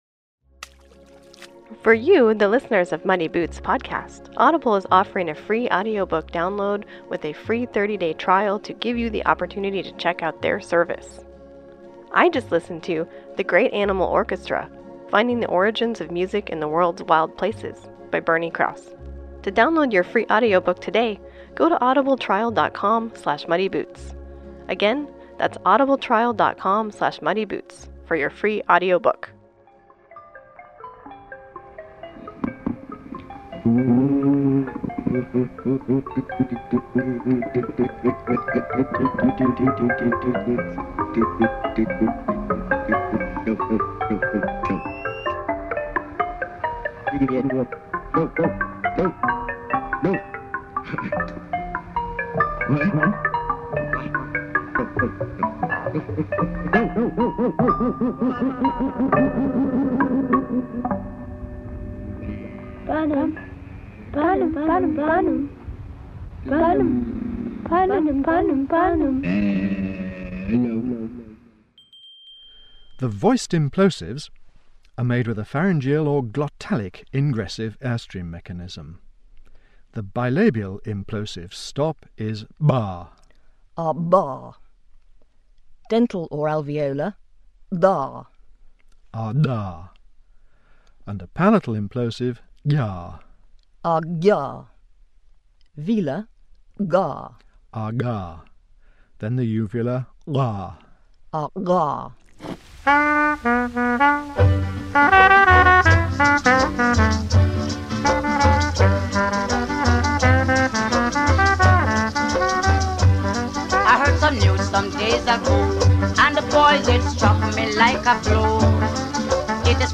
Come explore a hodge-podge of sound, snippet, and song.
So, as we dip our ears in an ink of aural oddities, may we commune with the muse as she sees fit.